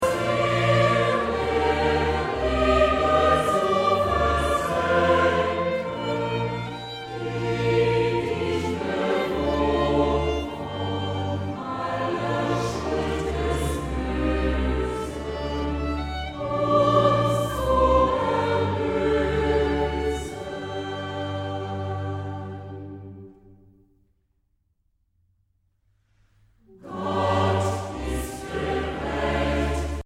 Interpret: Chor und Orchester